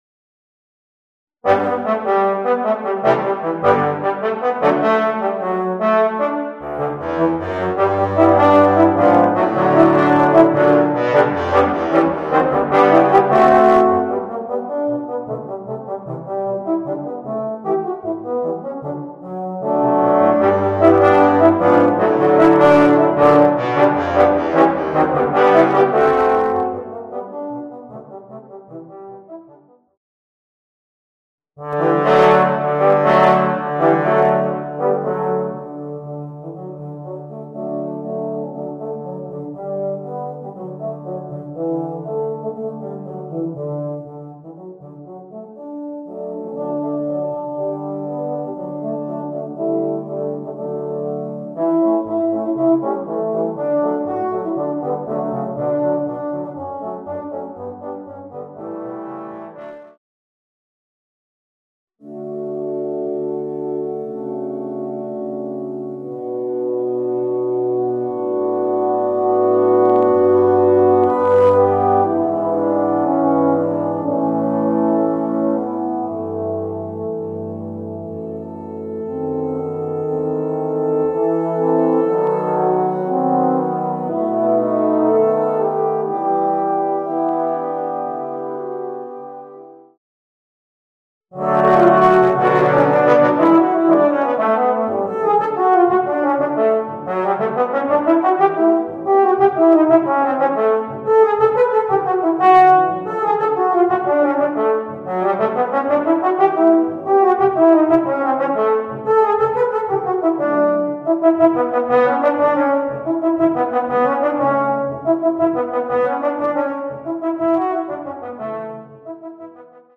Voicing: 4 Euphoniums